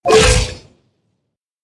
menu-direct-click.ogg